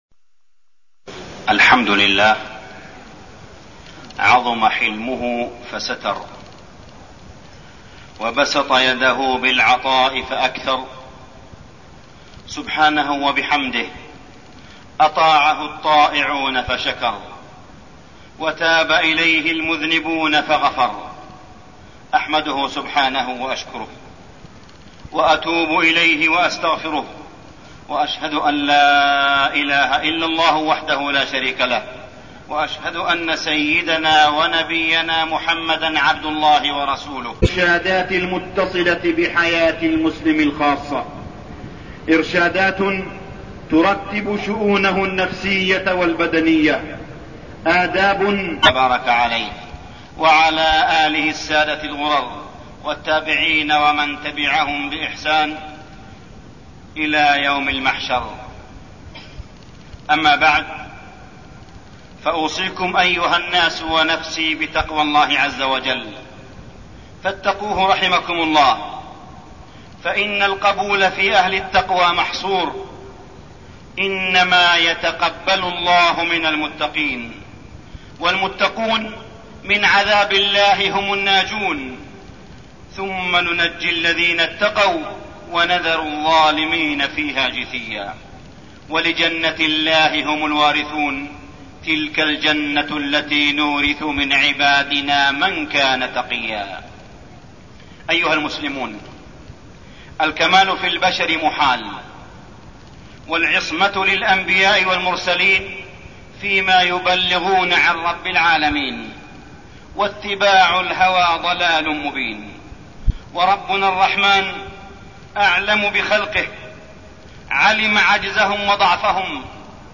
الخطب | موقع الشيخ صالح بن حميد